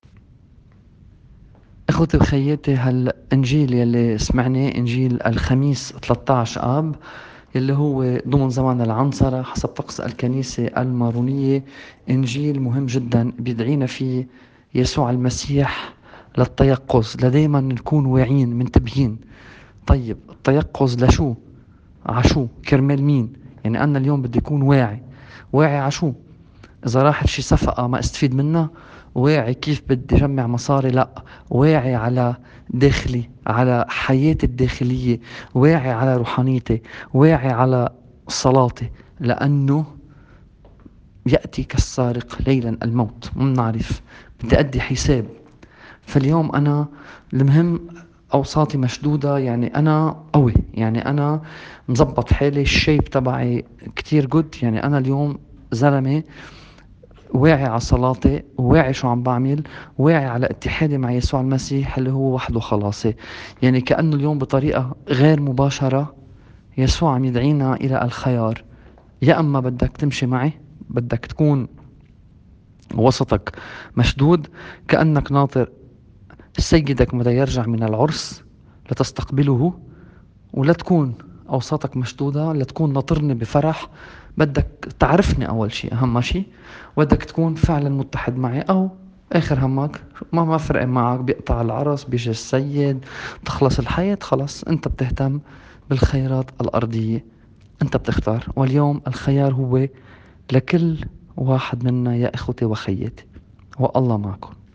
تفسير الإنجيل